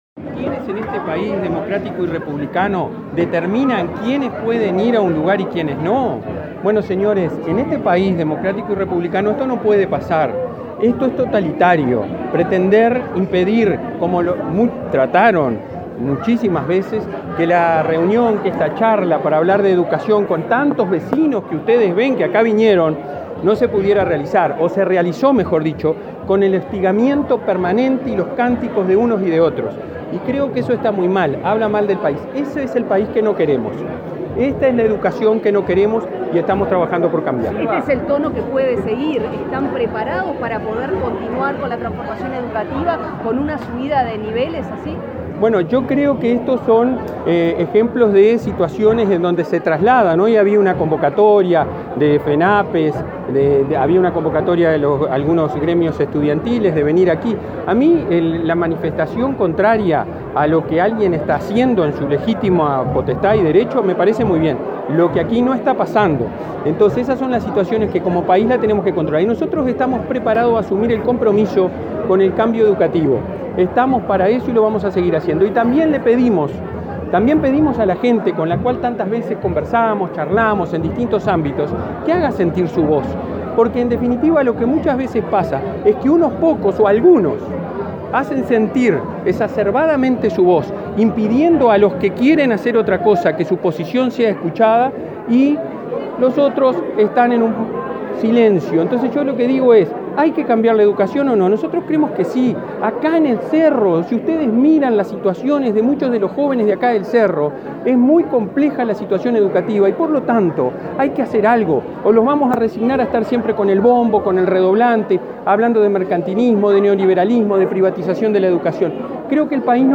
Declaraciones a la prensa del presidente del Codicen de la ANEP, Robert Silva
Tras participar en un Encuentro Cara a Cara con la Comunidad en el barrio Cerro, en Montevideo, este 1.° de setiembre, con el objetivo de dialogar sobre la transformación educativa, el presidente del Consejo Directivo Central (Codicen) de la Administración Nacional de Educación Pública (ANEP) realizó declaraciones a la prensa.